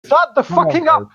shutthefckingup sound button getting viral on social media and the internet Here is the free Sound effect for shutthefckingup that you can download and make